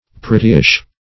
Prettyish \Pret"ty*ish\, a. Somewhat pretty.